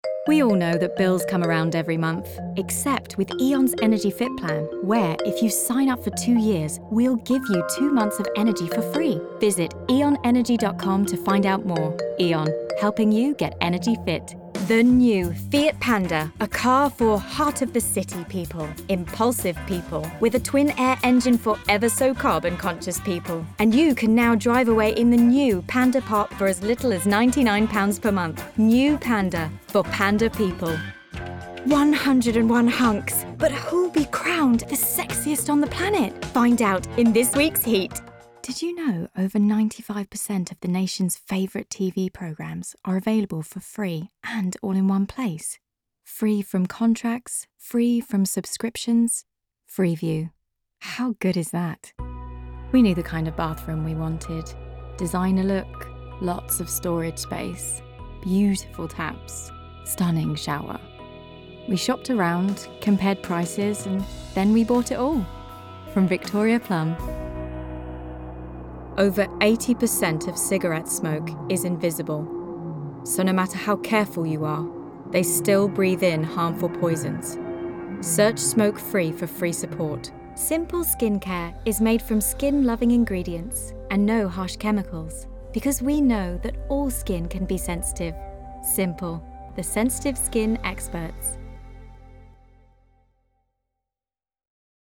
Voice Over Demos
Commercial U.K.